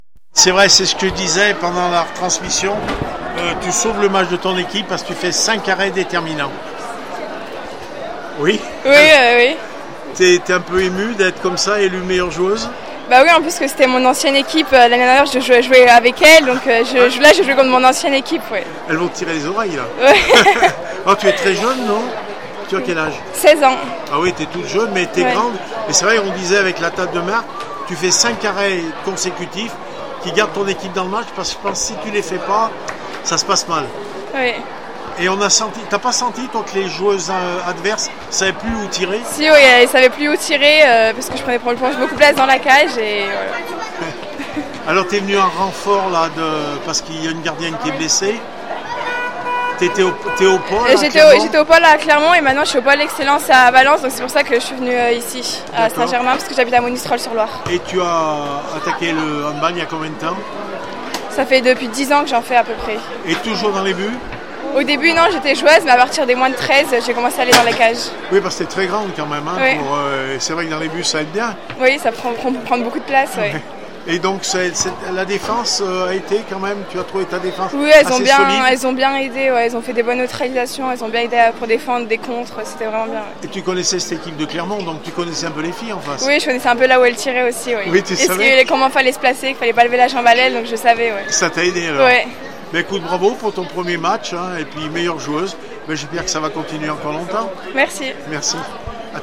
handball féminin nationale 2 st Germain laprade Blavozy 29-29 hb Clermont Auvergne métropole réactions après match 110922